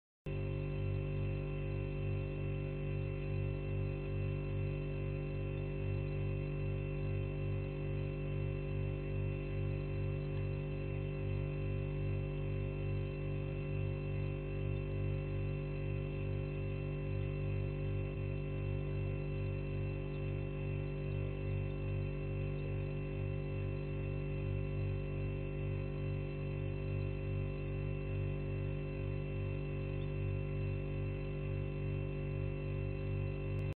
Звуки холодильника
На этой странице собраны звуки работающего холодильника: от монотонного гула до характерных щелчков и бульканья хладагента.